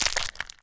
Minecraft Version Minecraft Version 25w18a Latest Release | Latest Snapshot 25w18a / assets / minecraft / sounds / block / frogspawn / hatch4.ogg Compare With Compare With Latest Release | Latest Snapshot
hatch4.ogg